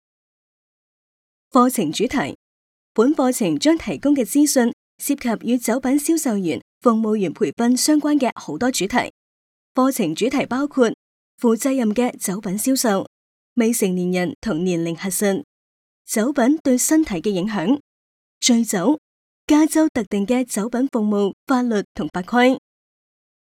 Cantonese_Female_003VoiceArtist_10Hours_High_Quality_Voice_Dataset